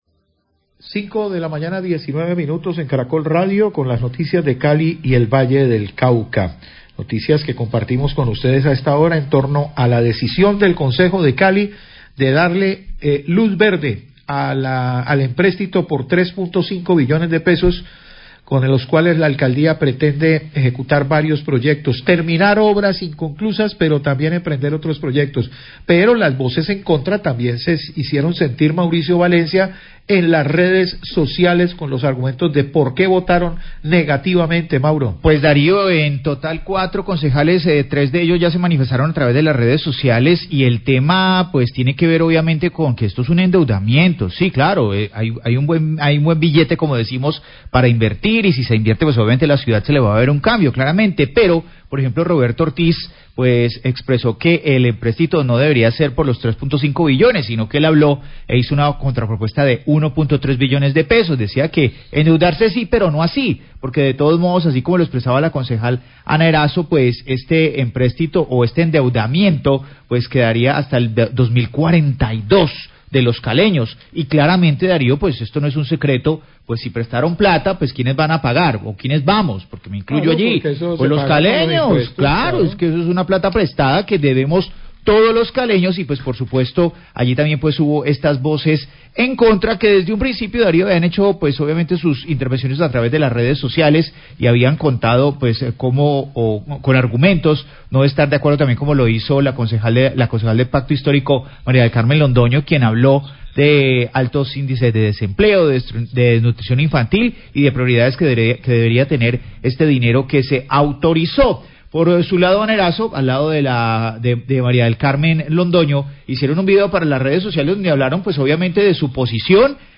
Radio
Concejales de Cali que votaron negativamente el proyecto de acuerdo para el empréstito presentado por la Alcaldía de Cali. Declaraciones de concejalas del Pacto Histórico donde expresan sus argumentos para tal votación.